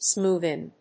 /ˈsmuðɪn(米国英語), ˈsmu:ðɪn(英国英語)/